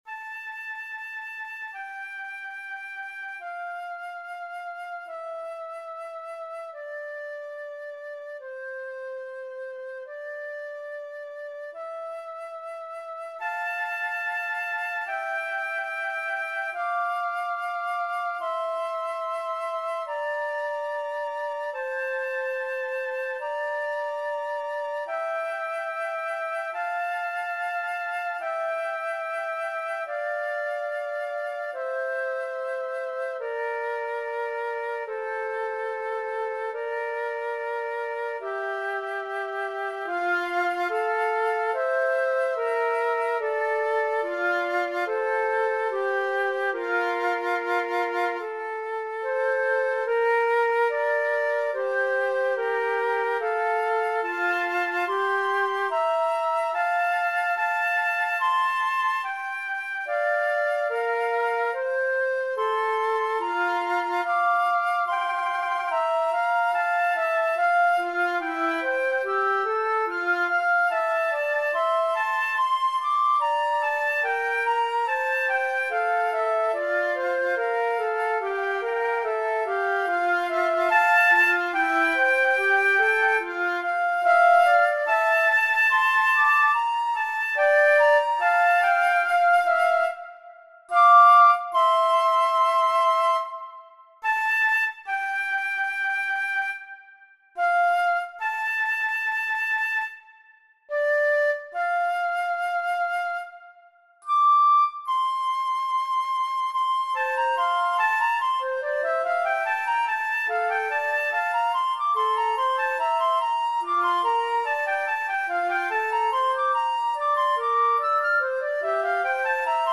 flute
This arrangement is for two flutes (duet).